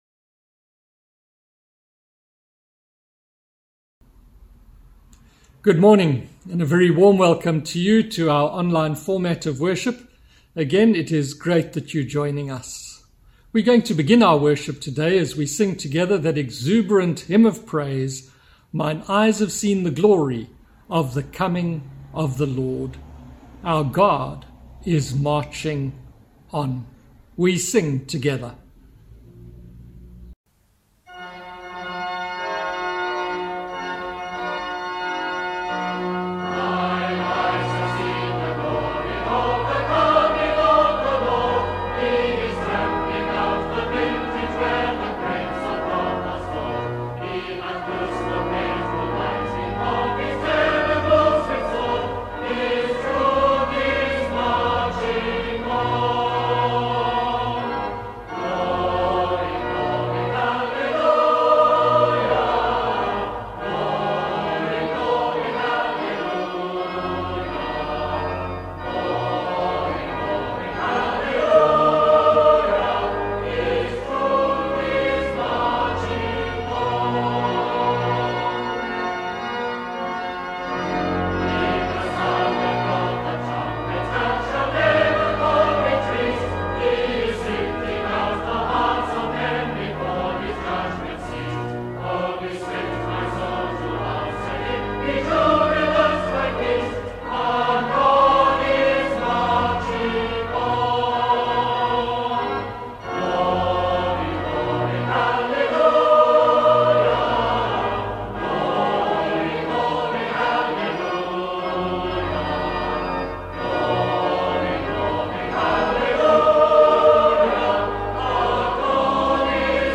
Sermon – 5th November – Need for intercession | Pinelands Methodist